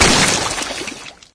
slimey_shotgun_01.ogg